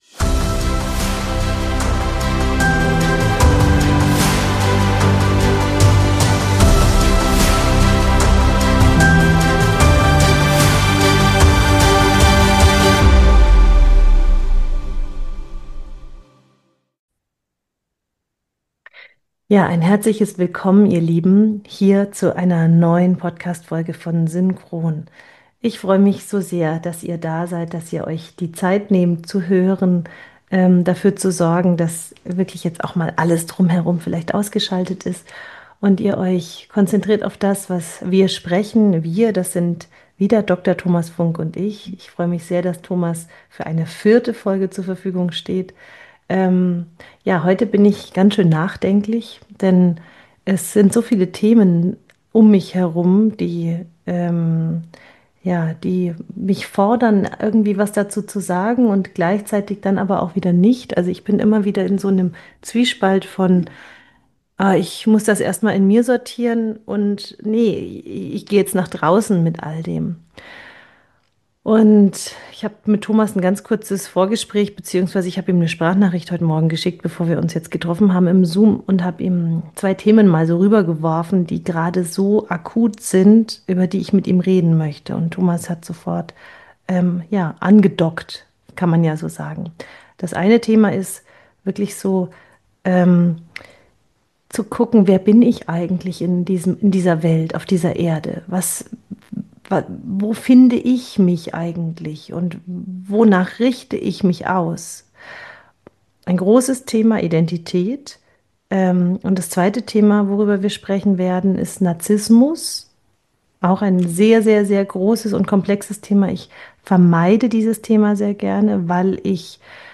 Diese Folge ist kein klassisches Interview, sondern ein ehrlicher, offener Dialog über das Menschsein in einer herausfordernden Zeit.